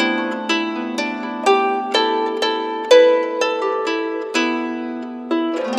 Harp09_124_G.wav